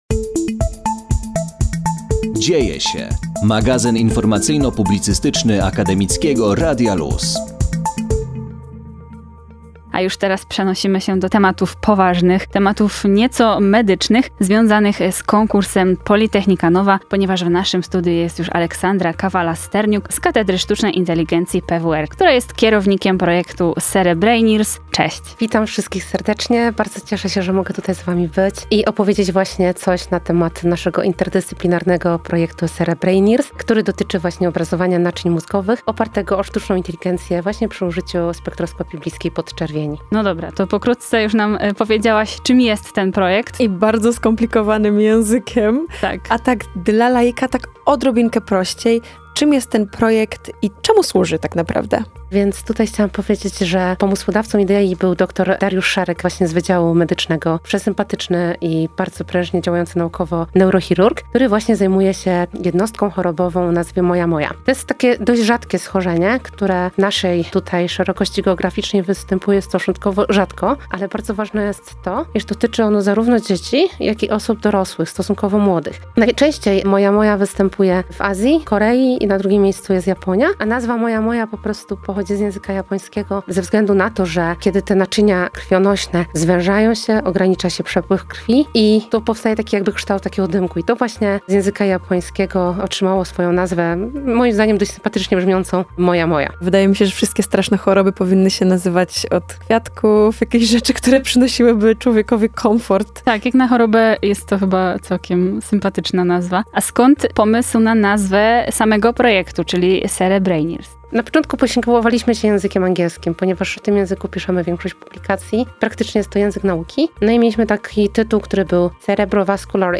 POLYTECHNICA NOVA – wywiady z laureatami pierwszego etapu konkursu
Wraz z nim ruszył nasz cykl rozmów z przedstawicielami zakwalifikowanych wniosków na antenie Akademickiego Radia Luz.